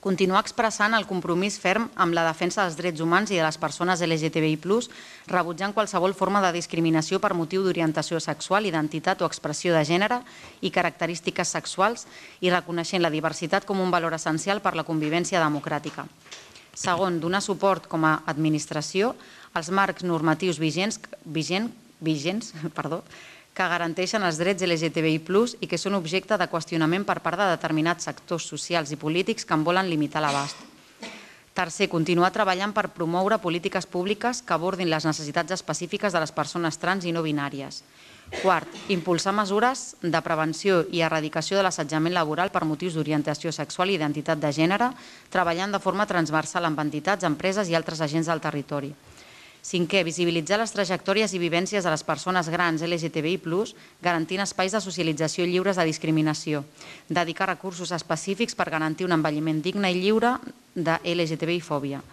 A més, volen fer visibles “les trajectòries i vivències de les persones grans”, explicava en la lectura del punt la portaveu de Movem Martorell, Laura Ruiz.
Laura Ruiz, portaveu de Movem Martorell